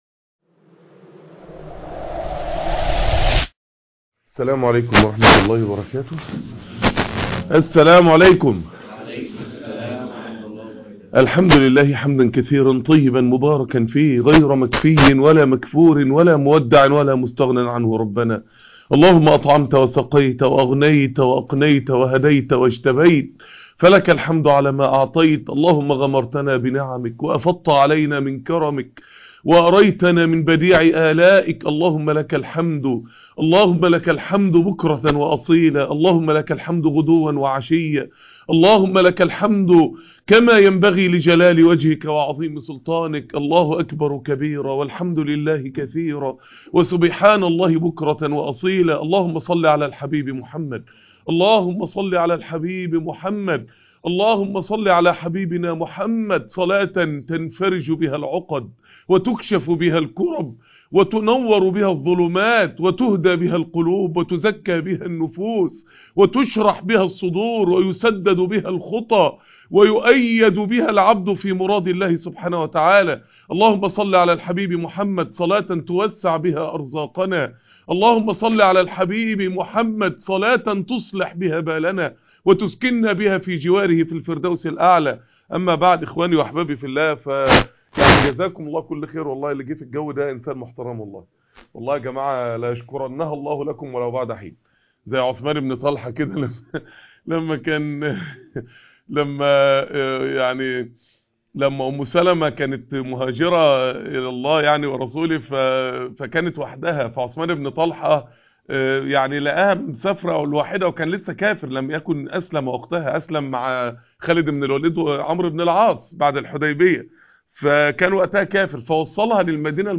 محاضرة هامة جدااااا ..